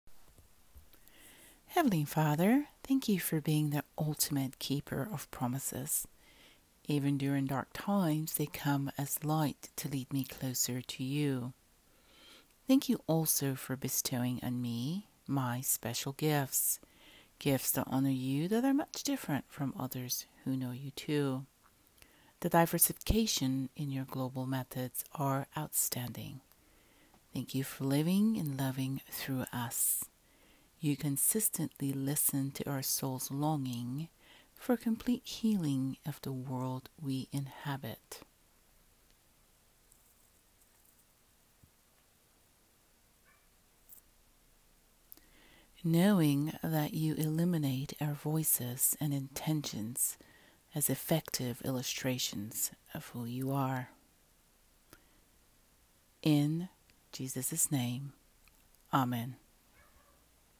My prayer:
Listen to me read this prayer: *my neighbor’s dogs are thankful too 🙂